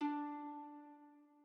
harp1_1.ogg